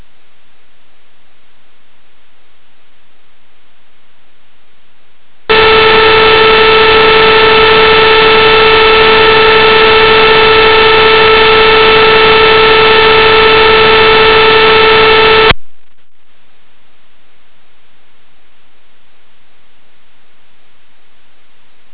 Silence sound silence
The wav attachment was the recording of streamming this track, plus silence.